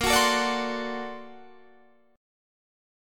Listen to Bb+M9 strummed